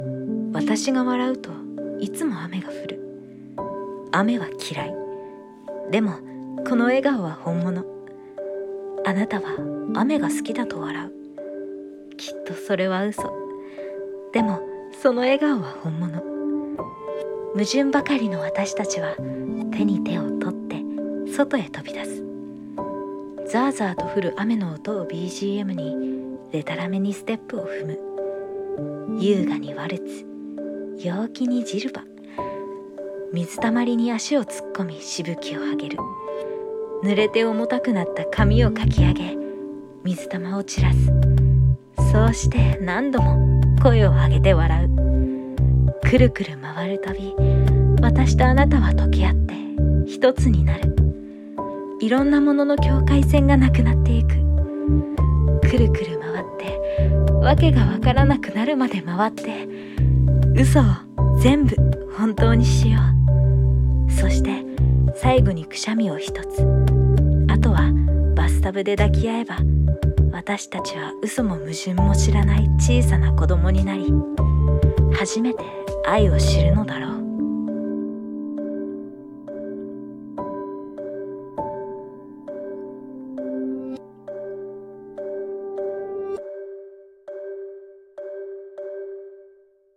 水と踊る (一人声劇／朗読)